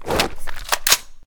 draw1.ogg